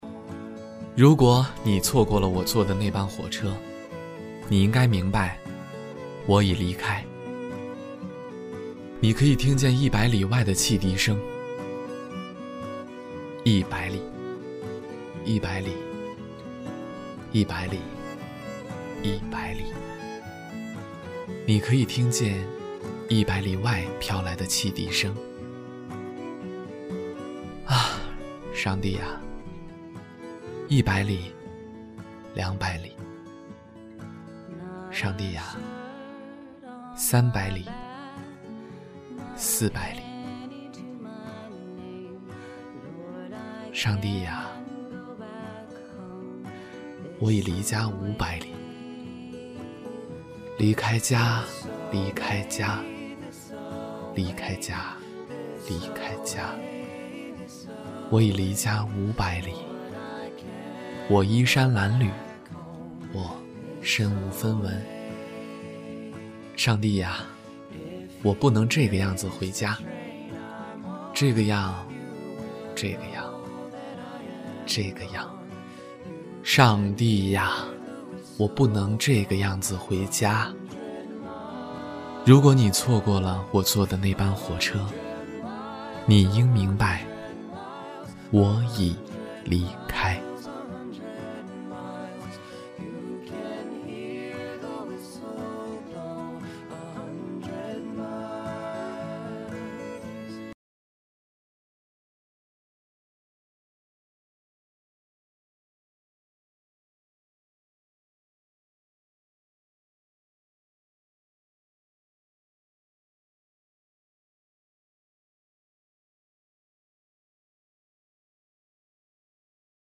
特点：年轻自然 素人 走心旁白
29男-微电影旁白-醉乡民谣-500里.mp3